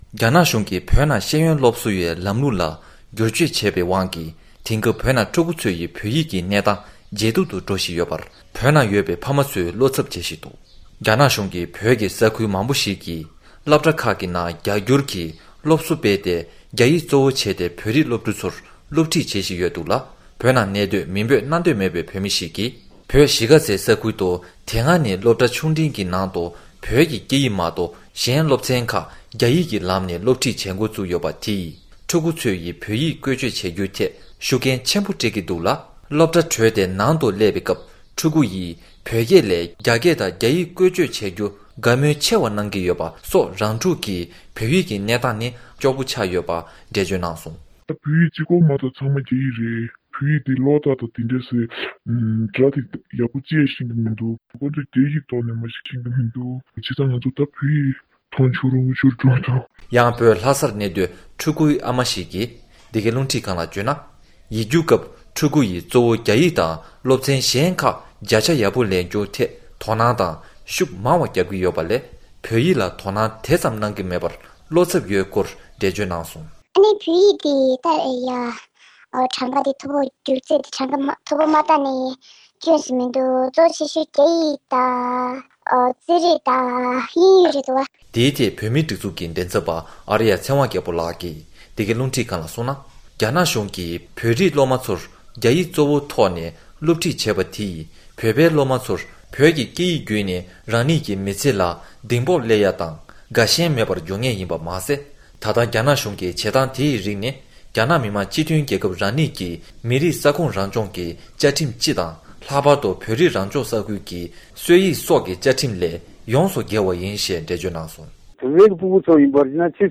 བོད་ནས་བཏང་འབྱོར།
སྒྲ་ལྡན་གསར་འགྱུར། སྒྲ་ཕབ་ལེན།